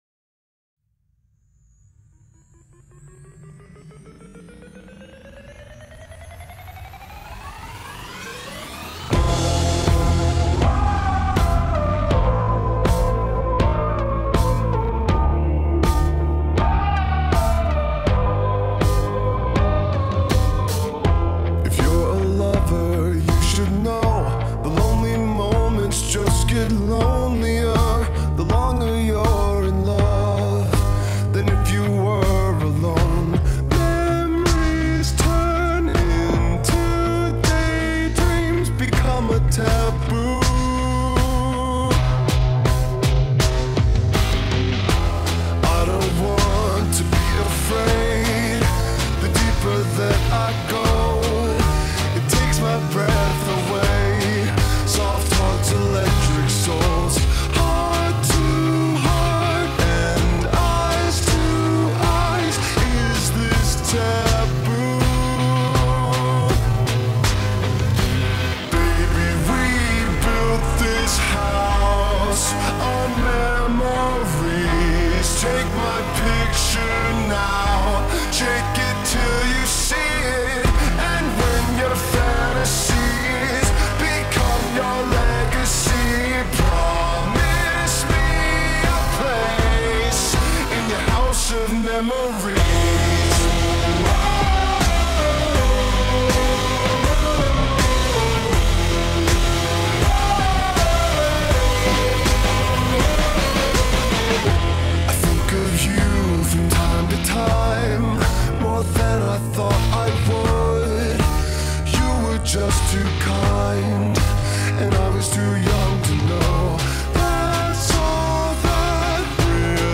با ریتمی آهسته شده